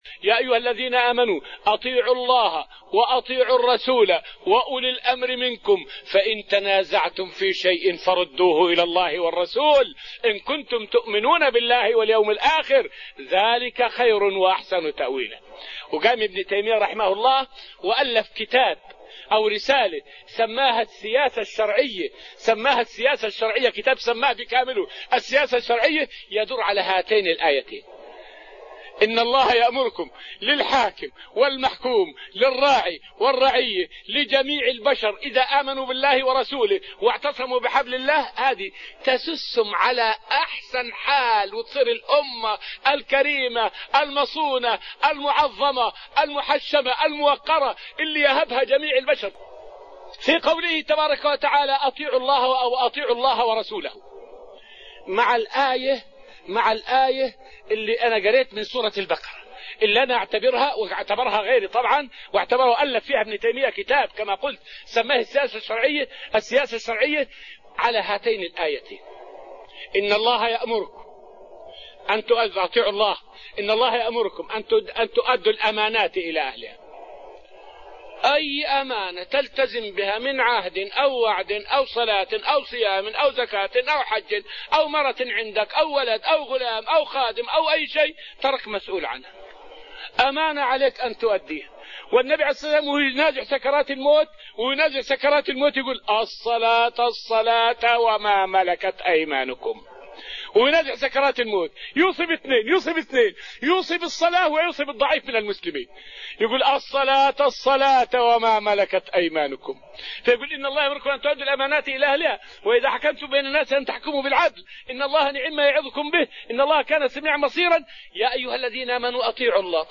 أحد حلقات البرنامج الإذاعي "دروس من الحرمين الشريفين" الذي كانت تبثه إذاعة القرآن الكريم من المملكة العربية السعودية، وتكلم الشيخ رحمه الله في هذا الدرس حول الوحدة بين المسلمين وأثر ذلك في نصر الإسلام وعزة المسلمين.